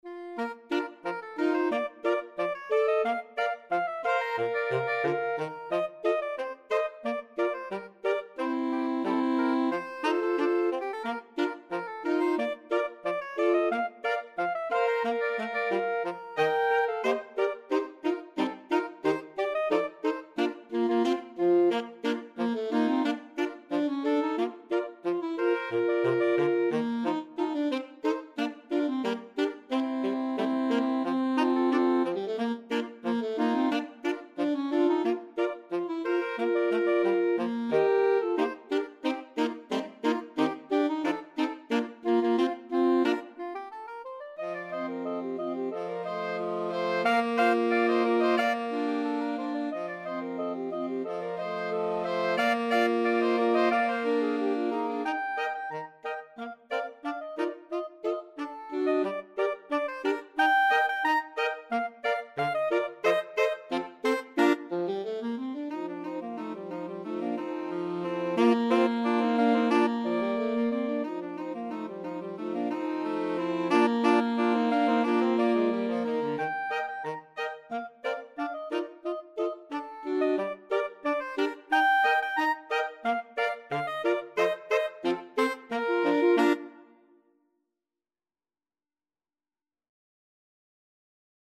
Free Sheet music for Saxophone Quartet
Soprano SaxophoneAlto Saxophone 1Alto Saxophone 2Tenor Saxophone
"American Patrol" is a popular march written by Frank White (F.W.) Meacham in 1885.
4/4 (View more 4/4 Music)
Bb major (Sounding Pitch) (View more Bb major Music for Saxophone Quartet )
Saxophone Quartet  (View more Intermediate Saxophone Quartet Music)
Traditional (View more Traditional Saxophone Quartet Music)